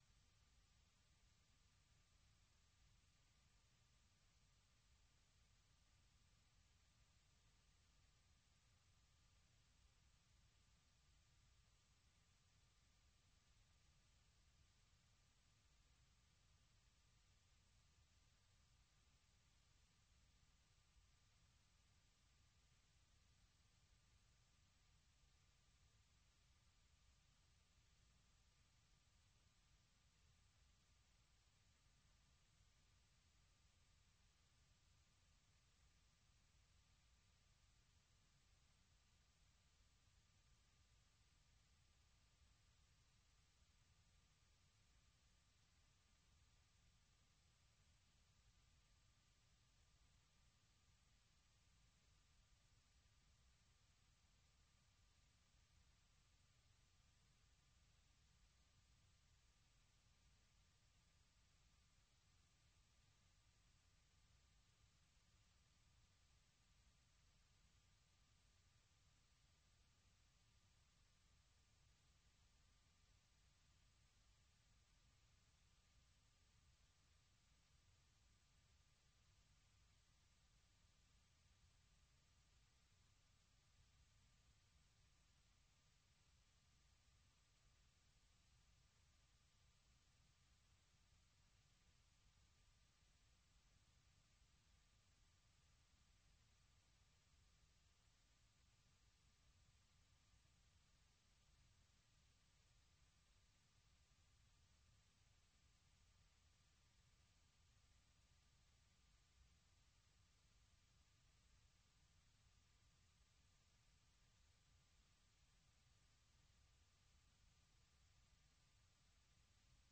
Allocution du président Donald Trump devant la session conjointe du Congrès